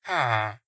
Minecraft Version Minecraft Version 1.21.5 Latest Release | Latest Snapshot 1.21.5 / assets / minecraft / sounds / mob / villager / idle1.ogg Compare With Compare With Latest Release | Latest Snapshot